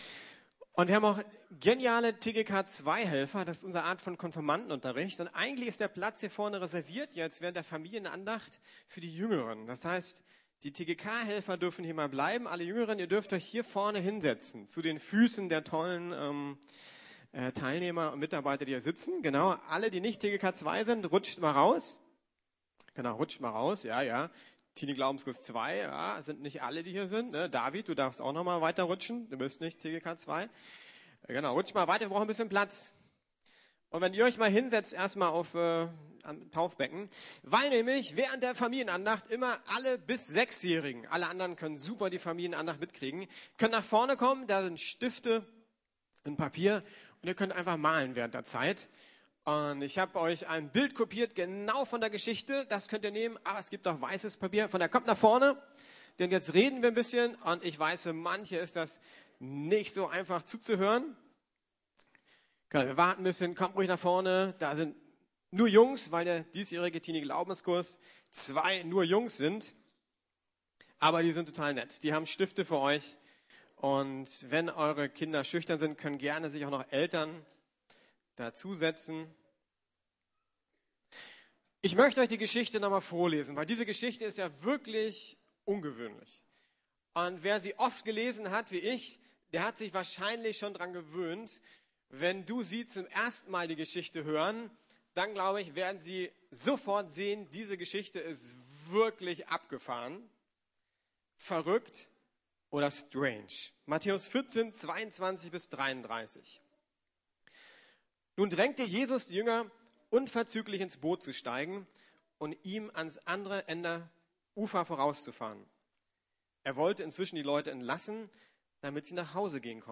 Auf wen schaust du? ~ Predigten der LUKAS GEMEINDE Podcast